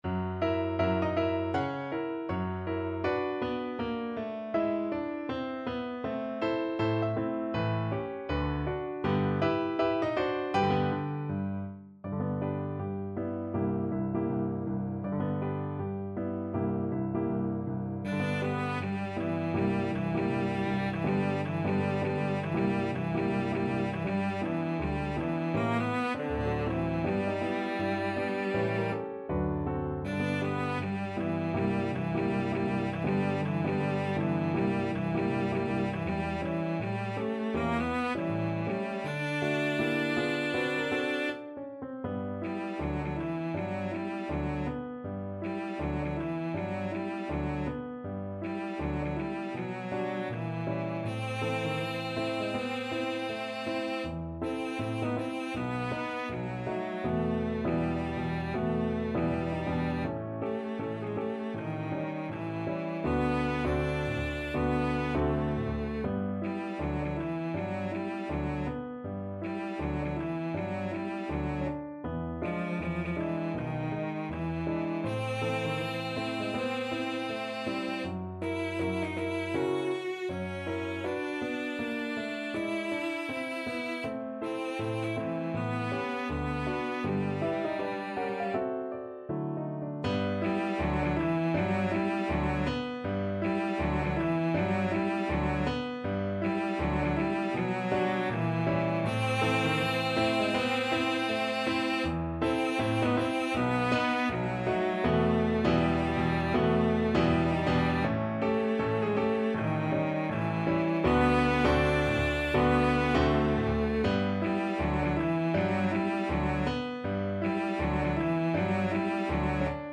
~ = 160 Moderato
2/2 (View more 2/2 Music)
Jazz (View more Jazz Cello Music)
Rock and pop (View more Rock and pop Cello Music)